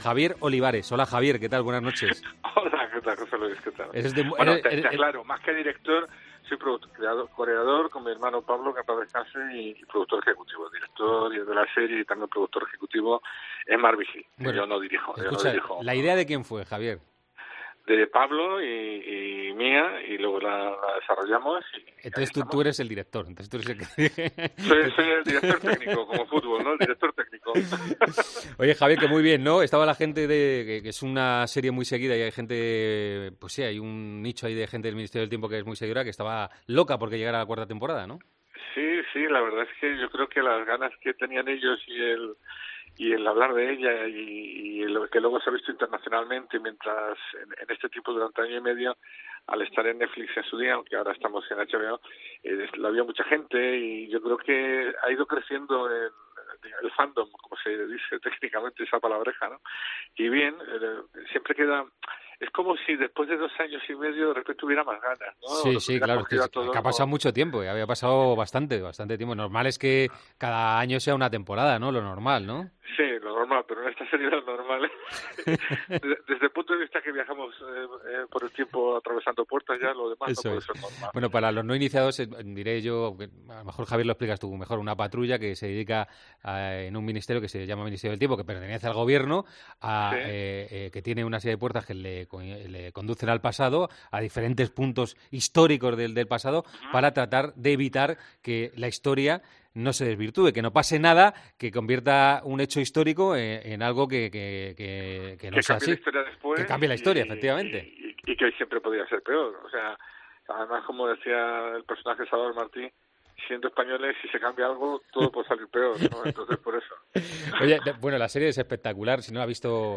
AUDIO: Charlamos con el cocreador de la serie 'El Ministerio del Tiempo' y seguidor del Atlético de Madrid.